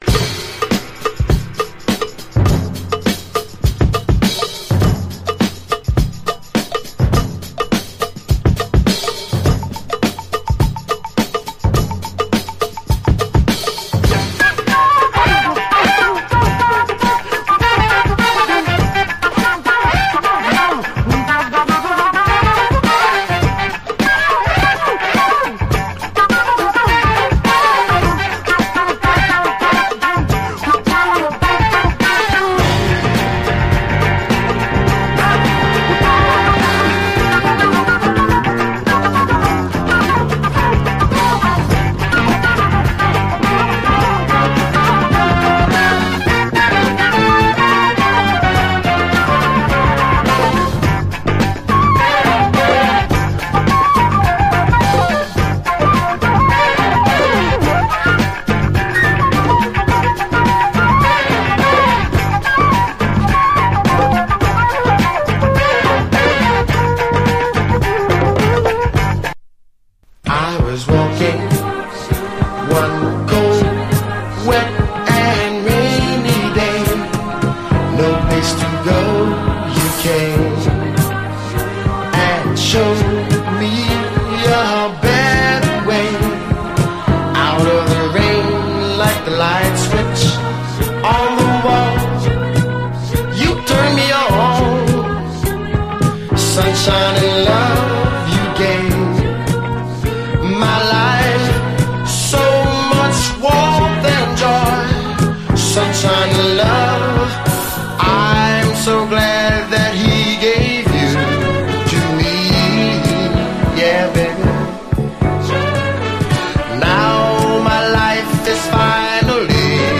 アフロ・レアグルーヴ〜アフロ・ブギー盤
ギコギコと不思議なサウンドのシンセ・フレーズが超ヤバい。
ファンク、ジャズ、アフリカが渾然一体となったこのグルーヴ！